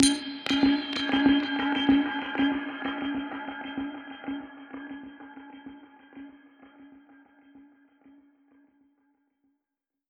Index of /musicradar/dub-percussion-samples/95bpm
DPFX_PercHit_D_95-04.wav